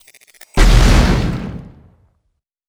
Grenade4.wav